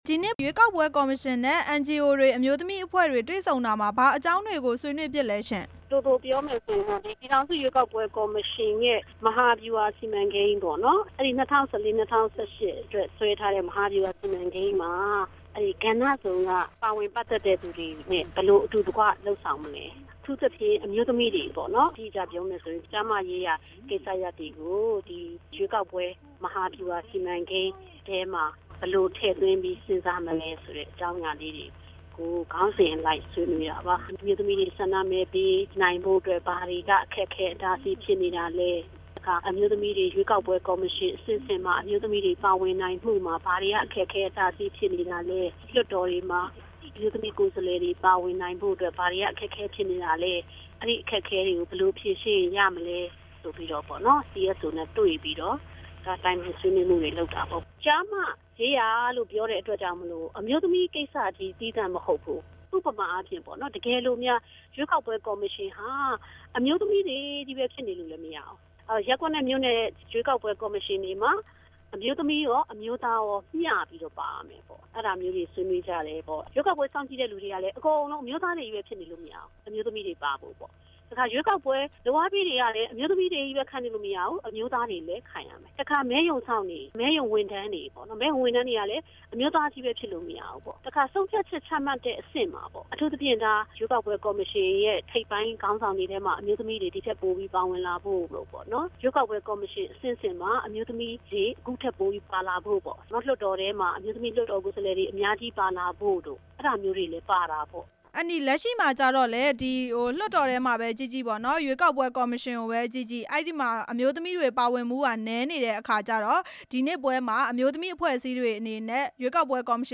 ဒေါက်တာ ညိုညိုသင်းနဲ့ မေးမြန်းချက်